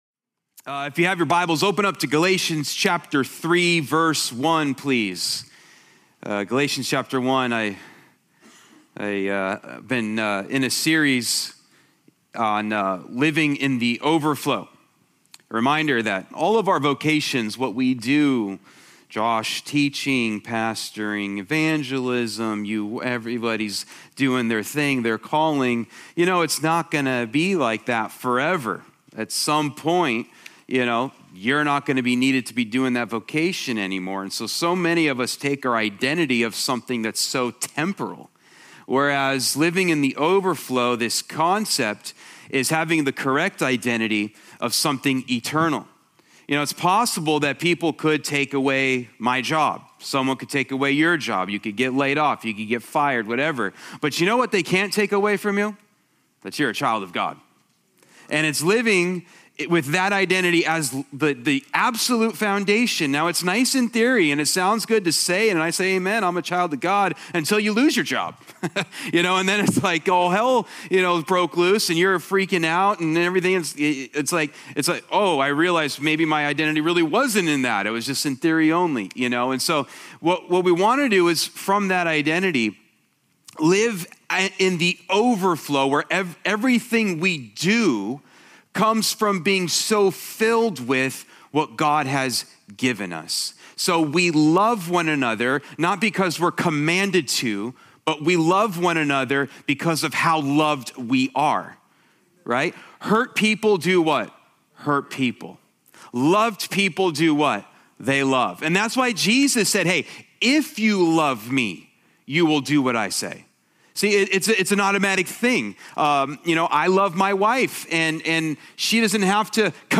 Sermons | Church of Grace